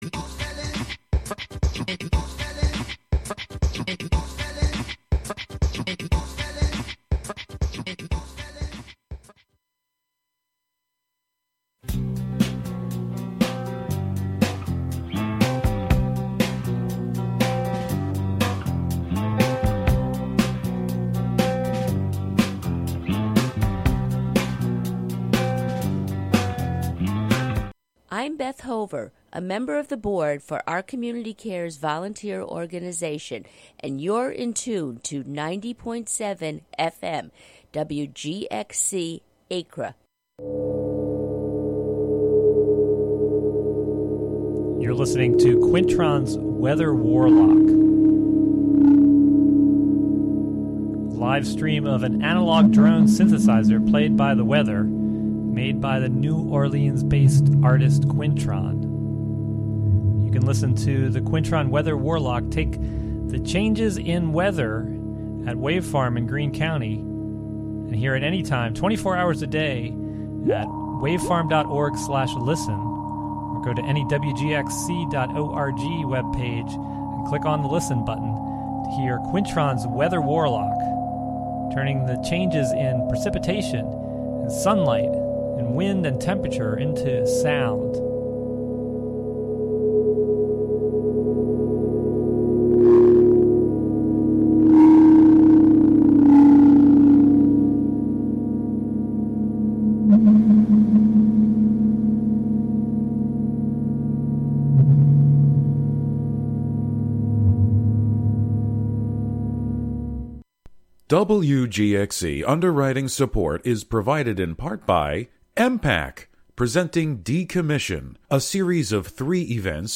--- "Beakuency" invites the local community to be inspired by the joy, beauty, and wisdom bird enthusiasts find in the nature of our neighborhoods. Every episode features an interview with local bird people, plus a freeform mix of sound made by birds and humans inspired by birds.